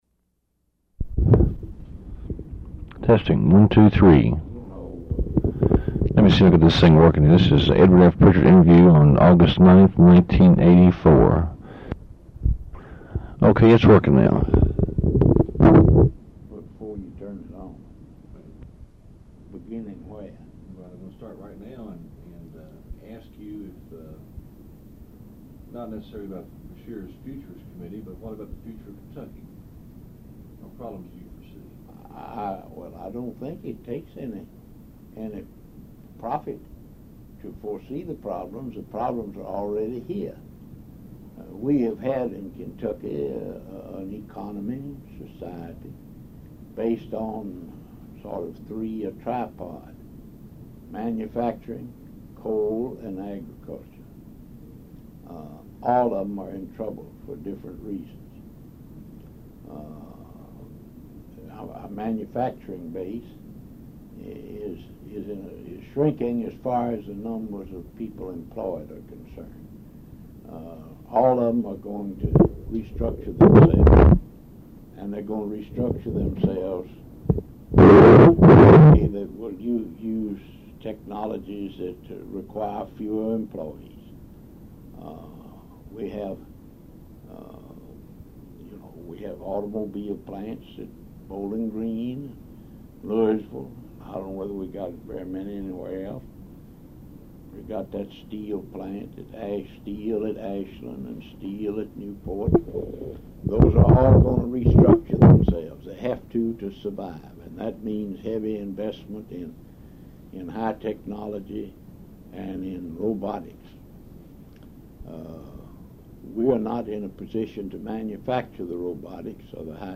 Oral History Interview with Edward F. Prichard, Jr., August 9, 1984